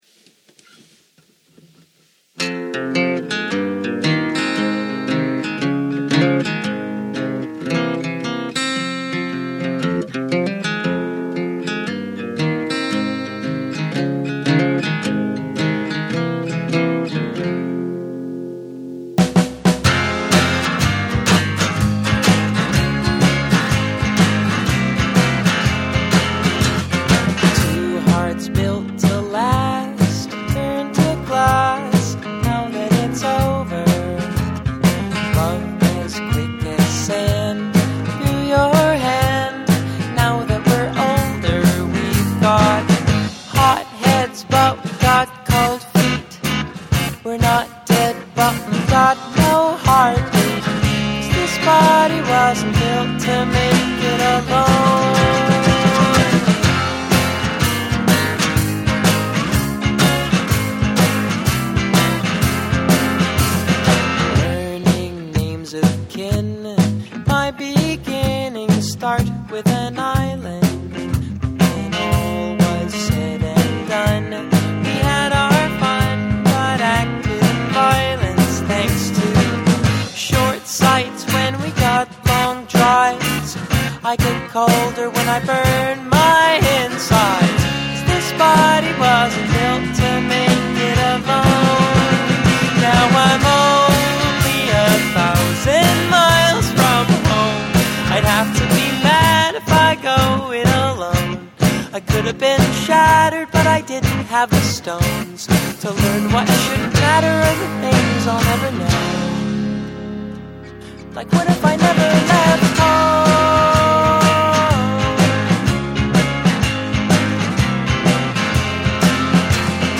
I recorded one of my originals to see what kind of sound I could get using my own gear and space. I recognize that it's far from a finished product, but I'd like to know if I'm developing any bad habits at home.